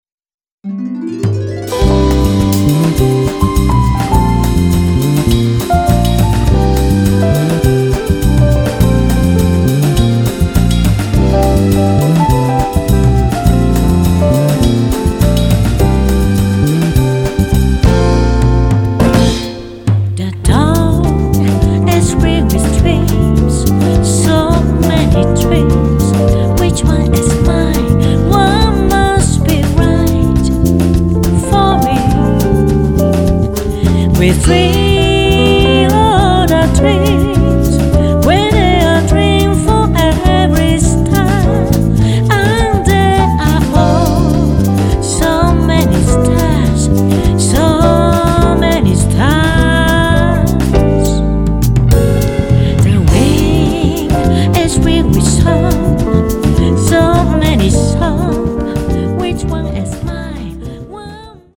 ボーカリスト担当